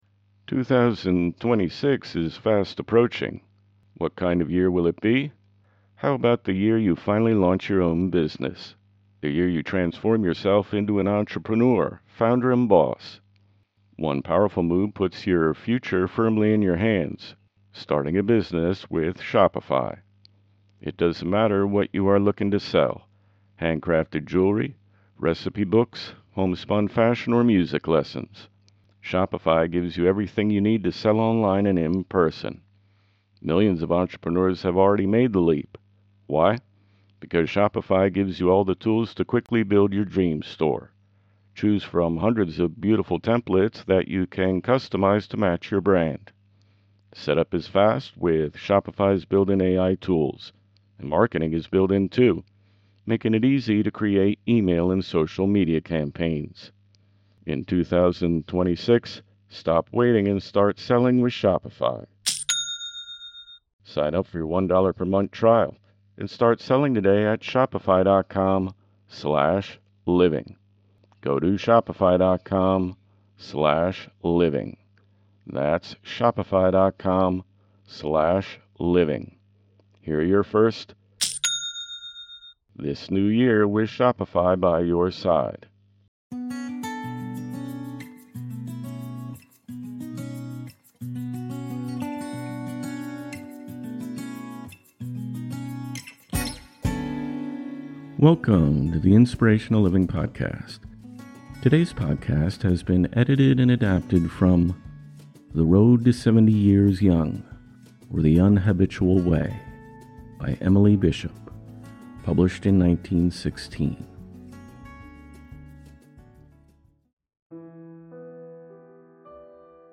Inspirational podcasts from the most uplifting authors of the past.